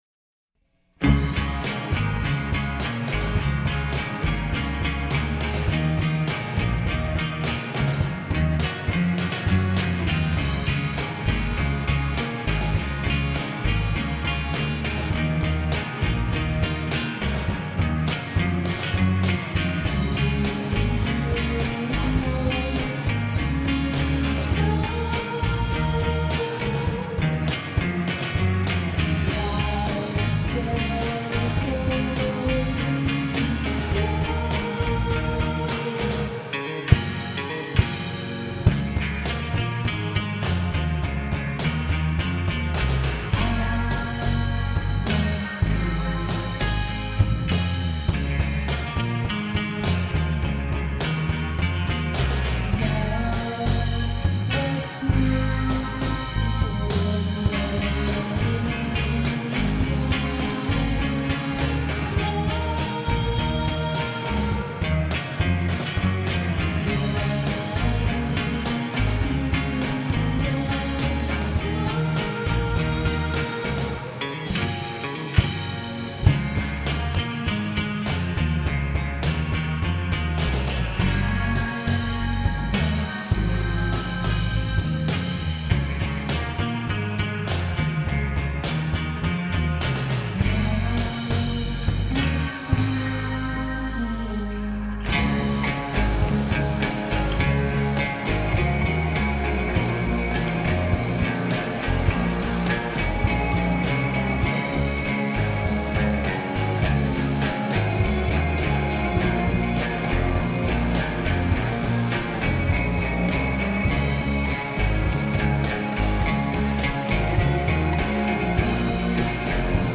Il quartetto di Atlanta